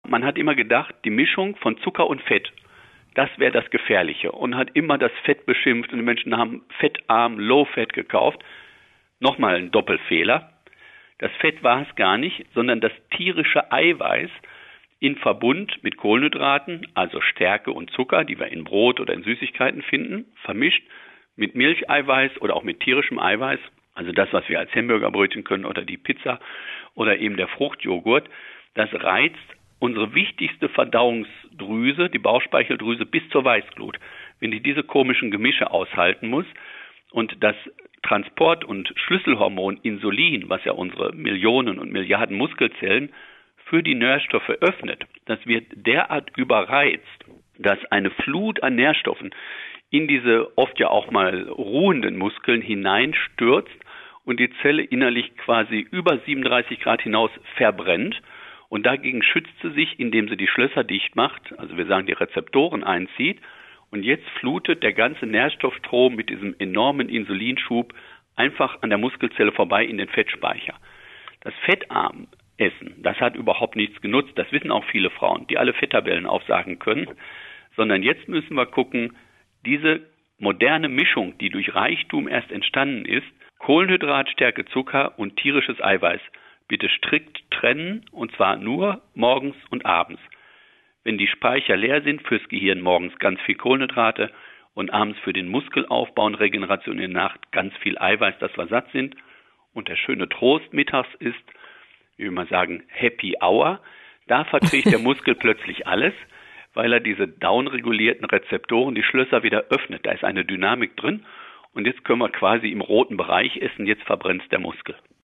SWR3-Audio: Beitrag anhören